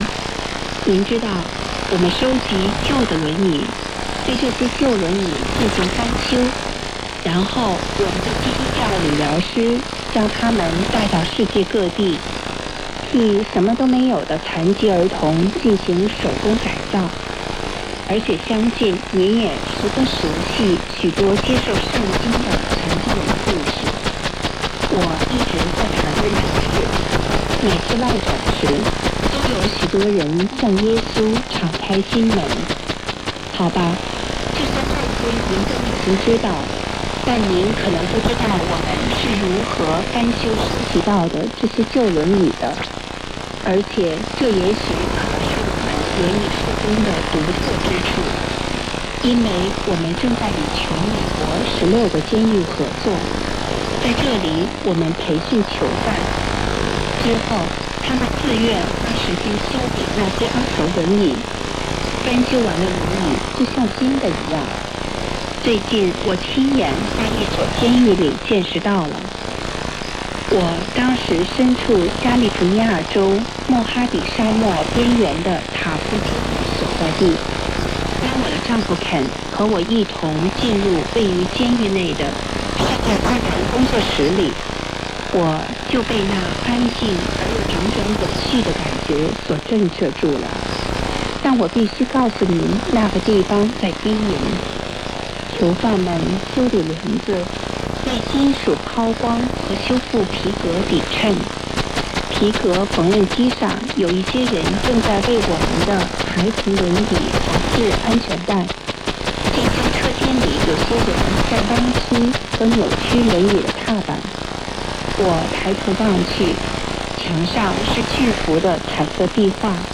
WORLD CHRISTIAN BROADCAST BEING JAMMED BY CHINA.
LOC: MADAGASCAR, MAHAJANGA, 15.43 S 46.19 E